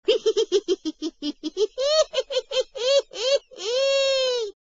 • Funny Ringtones